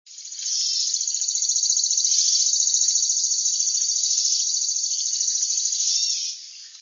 Chipping Sparrow
sparrow_chipping_song655.wav